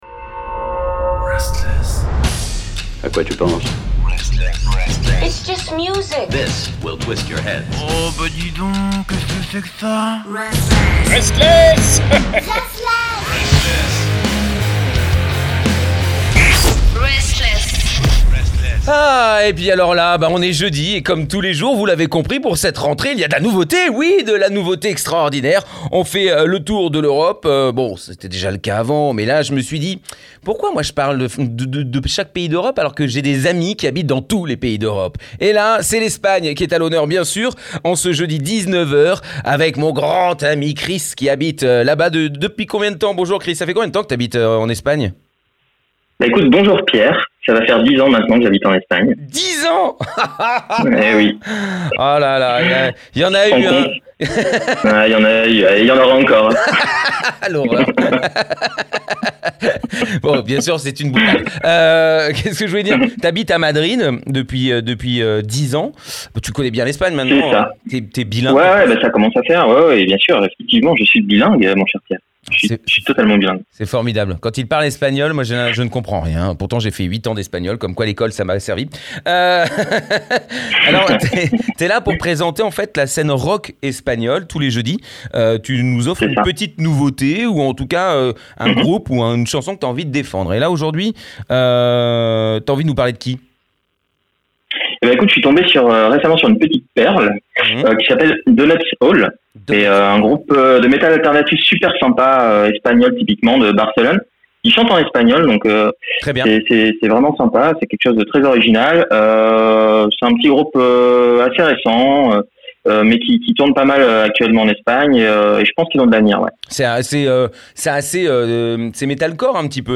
Aujourd’hui, c’est du groupe de métal alternatif catalan DONUTS HOLE qu’on vous fait découvrir. DONUTS HOLE c’est un groupe espagnol et chantant en espagnol formé en 2009 à Barcelone par des membres d’origine très différentes (Argentine, Japon, Espagne, Uruguay) et aux influences très diverses allant de l’indus, du neo metal, du metalcore, et même vers le punk/rock…